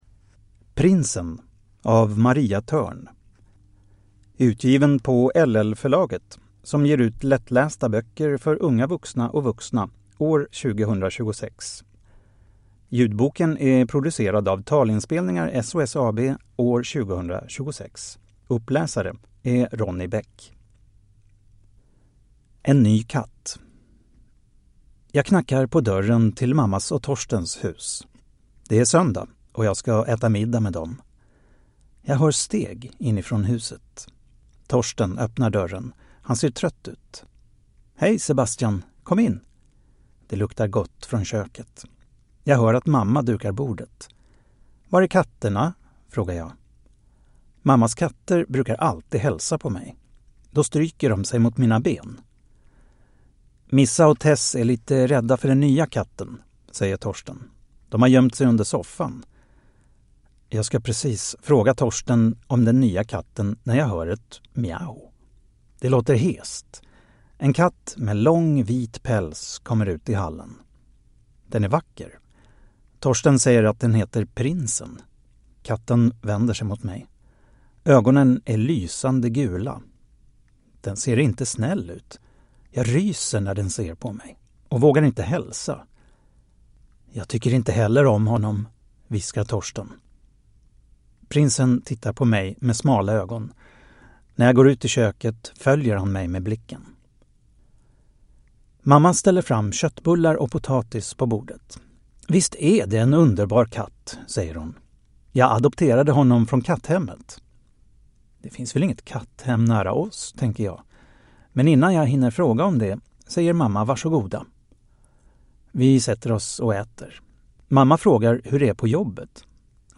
Prinsen (lättläst) – Ljudbok